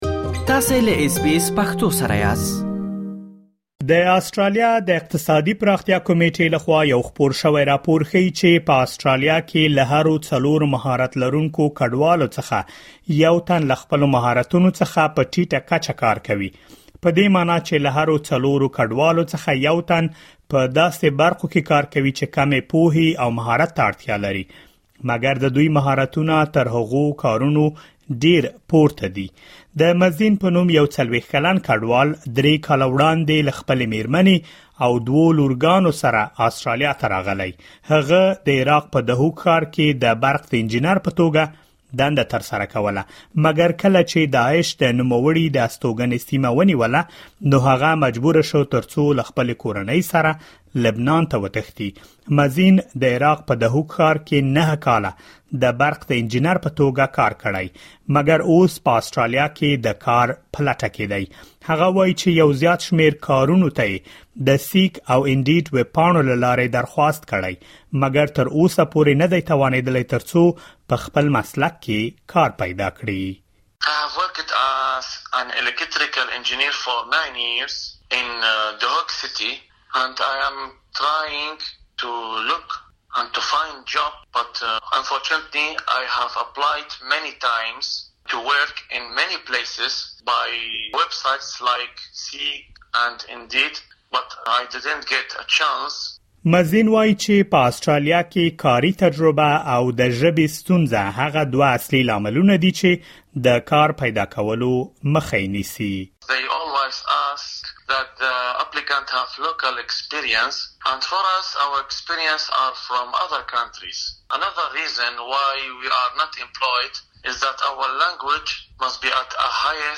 راپورونه ښيي چې په آسټرالیا کې له هرو څلورو مهارت لرونکو کډوالو څخه یو تن له خپلو مهارتونو څخه په ټیټه کچه کار کوي. په دغه رپوټ کې په خپل مسلک کې د کار پیدا کولو په اړه معلومات اورېدلی شئ.